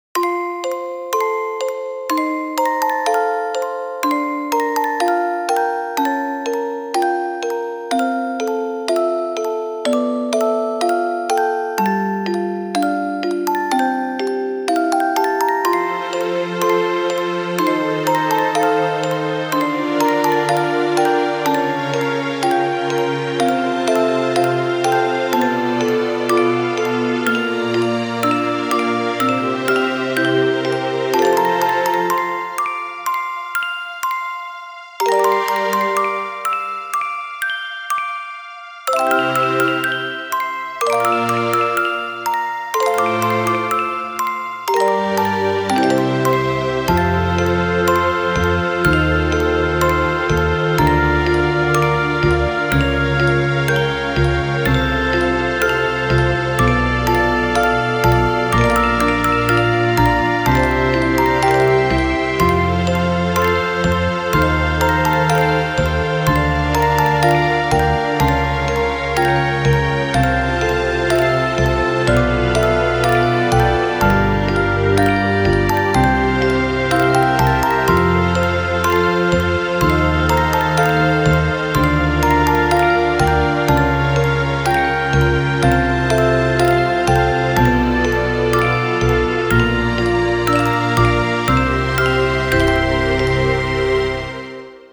ogg(L) しっとり 幻想的 オルゴール協奏曲